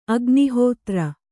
♪ agnihōtra